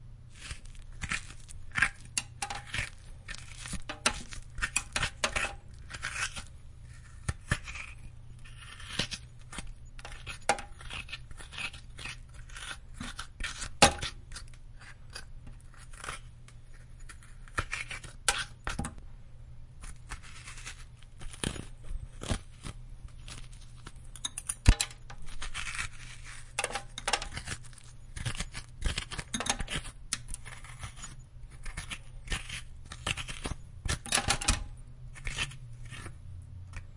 描述：在我关闭进入浴缸的水后，我听到的就是这种声音。用连接在进入浴缸的水龙头上的Cold Gold接触式麦克风录制的，放入Zoom H4录音机中
标签： 胡萝卜 接触 食品 厨房 纸切割器 剥离器 刨床
声道立体声